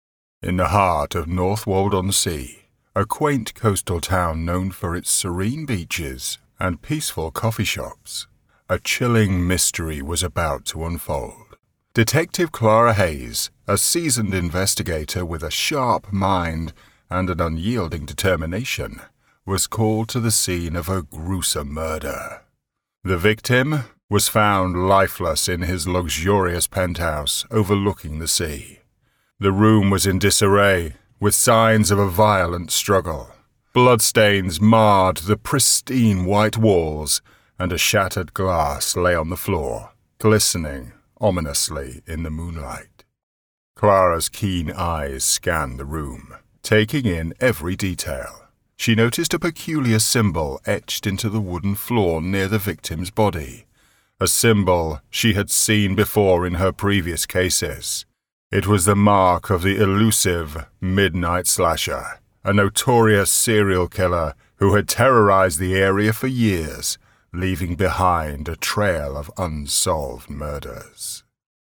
Naturally deep, warm and friendly, distinguished, charismatic, versatile
Audiobook